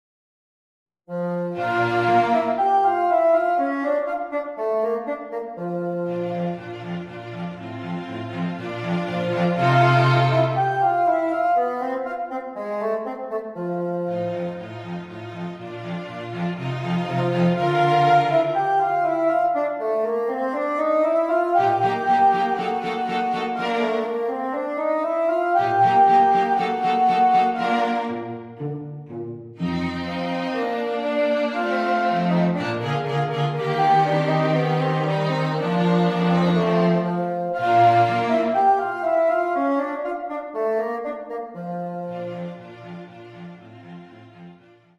bassoon, violin, viola, violoncello
(Audio generated by Sibelius/NotePerformer)